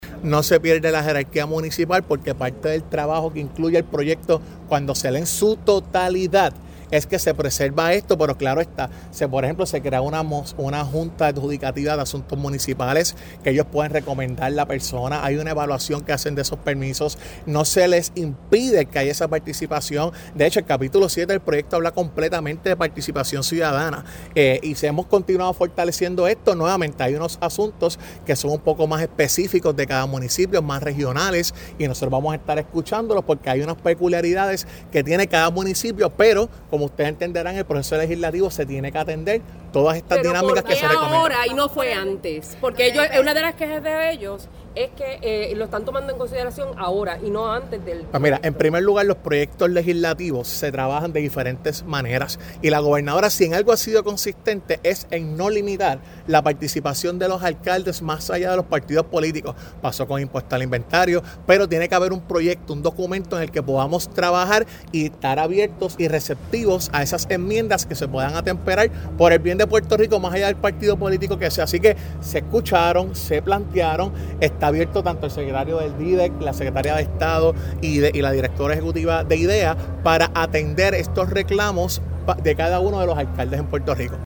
“El proyecto no le quita a los municipios su jerarquía “, dice el titular de Asuntos Públicos tras reunión con la Asociación de Alcaldes (sonido)